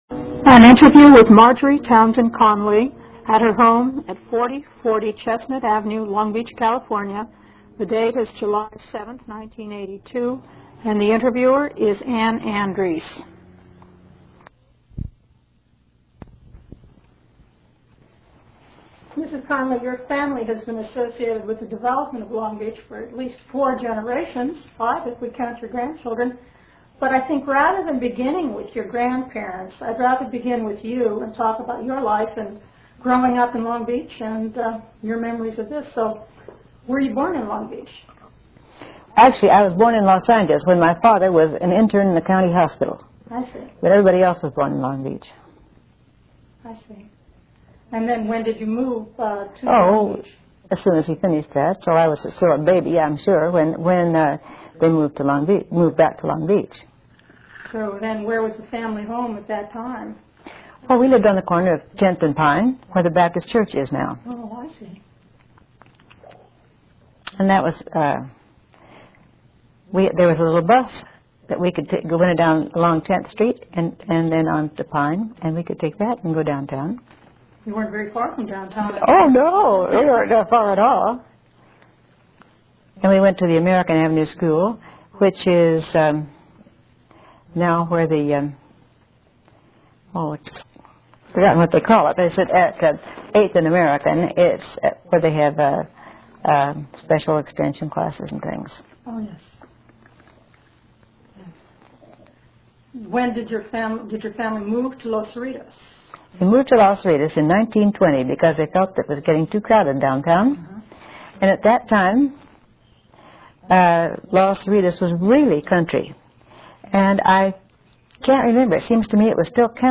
Introduction.